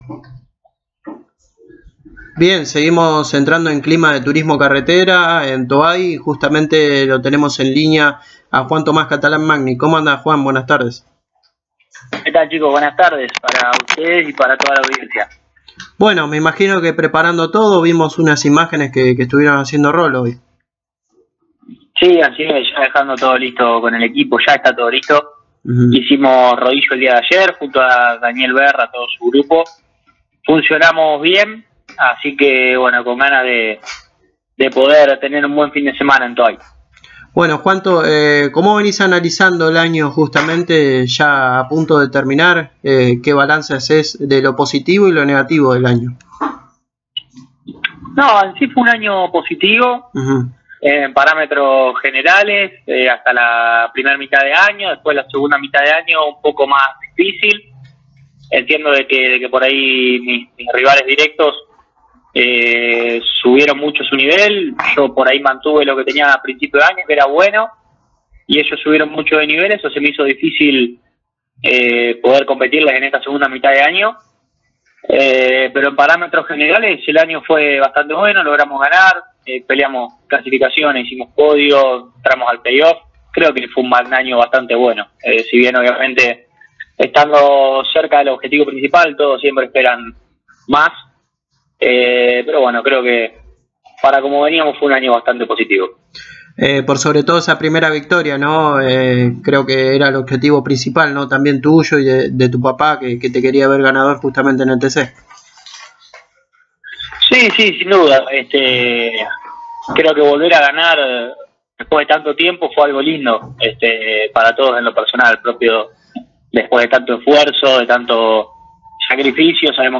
El piloto de Arrecifes pasó por los micrófonos de Pole Position y habló de como se prepara para el fin de semana en Toay. Además, habló de como se prepara para la próxima temporada y la idea de armar un Ford Mustang nueva generación.